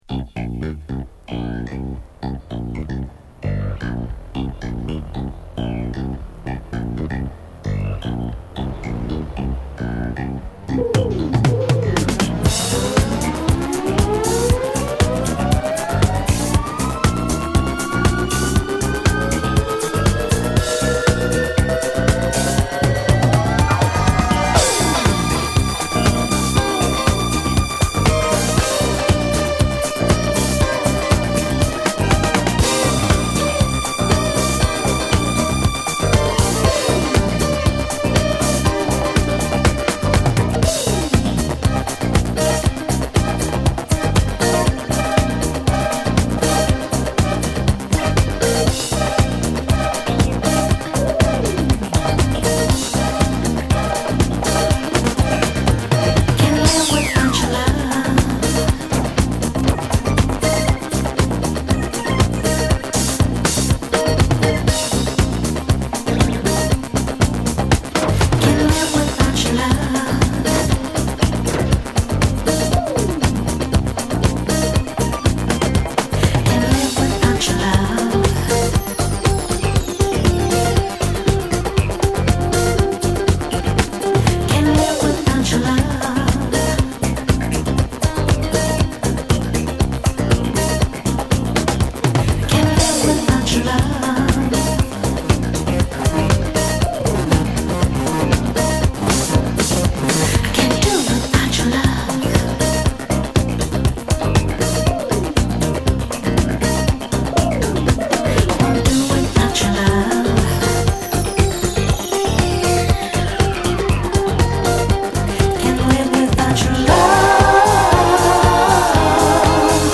ダンサブルなベースとタイトなリズム、ピュンピュン飛び交うシンセもカッコ良い文句無しのクラシックチューン！
ミッドテンポの